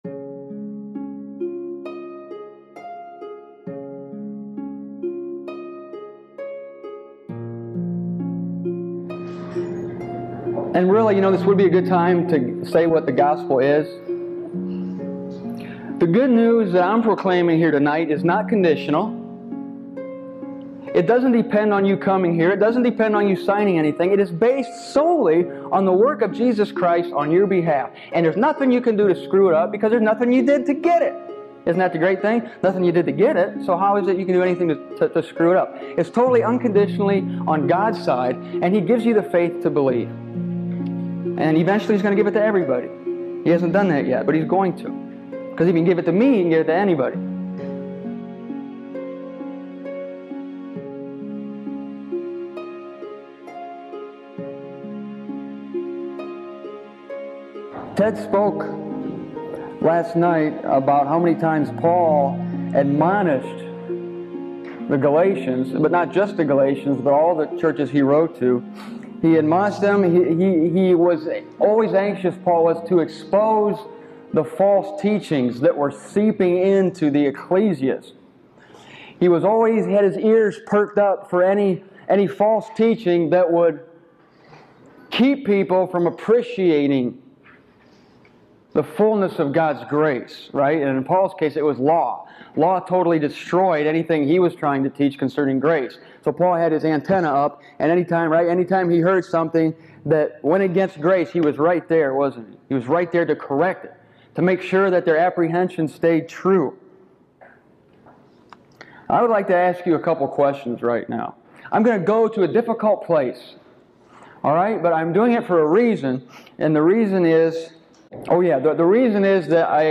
This is the recording of that event.